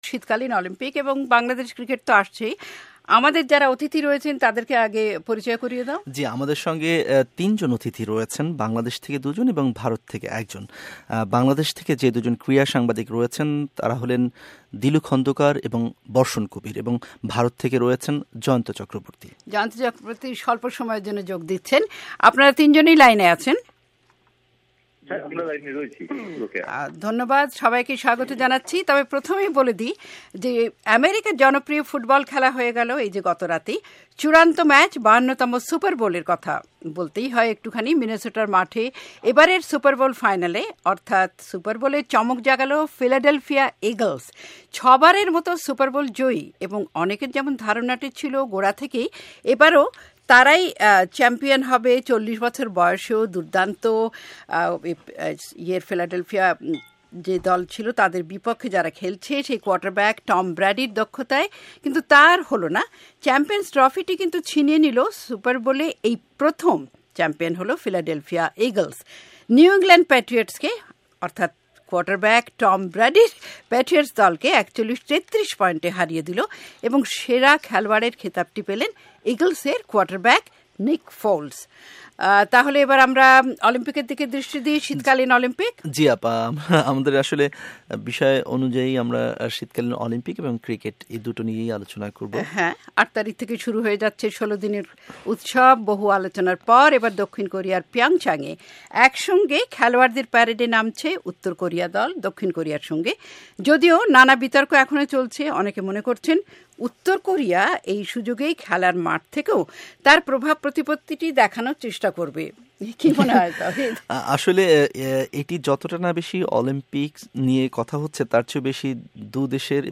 এবং ভারত থেকে অতিথি ছিলেন ক্রীড়া সাংবাদিক